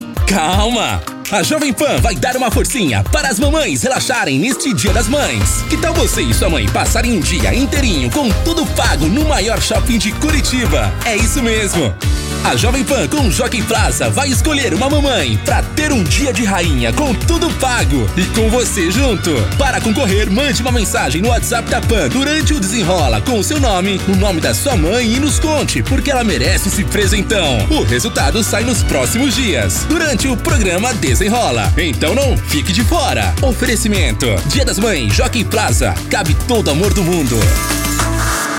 CHAMADA PARA RÁDIO :
Impacto
Animada